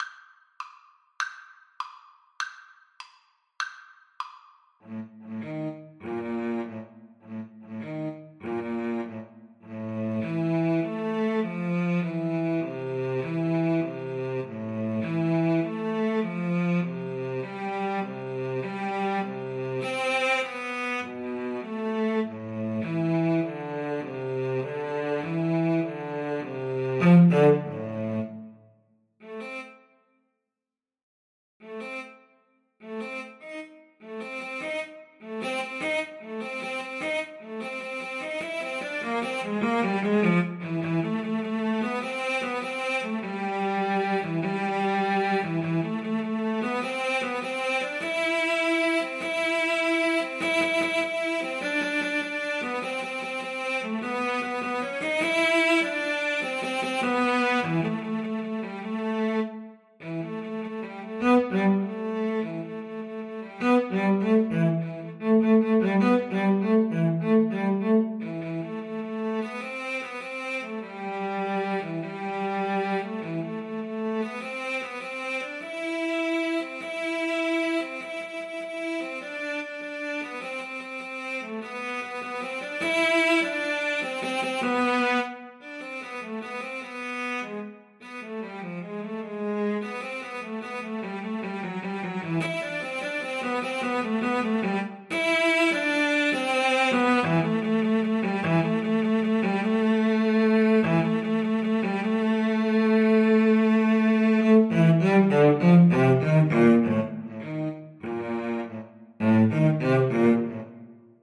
6/8 (View more 6/8 Music)
Quick and menacing (. = c.100)
Classical (View more Classical Violin-Cello Duet Music)